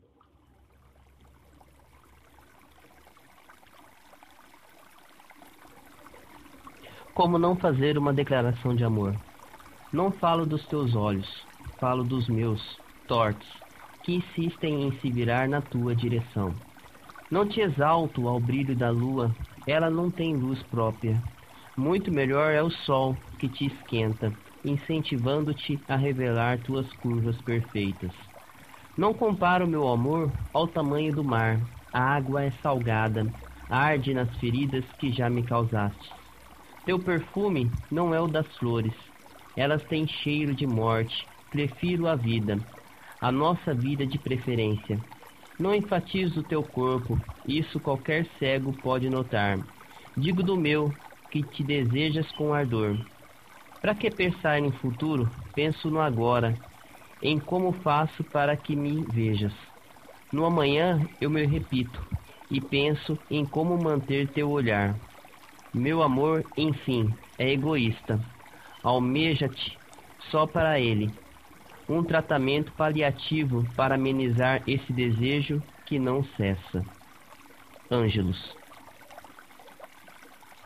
Música de fundo retirada da internet.